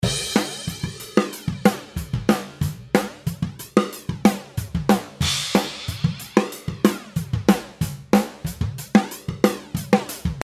2. グライドでLFOの波形が左だと矩形波のように、右だとサイン波のようなスムースな波形になります。
上の画像のセッティングでドラムを流してみました。